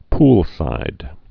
(plsīd)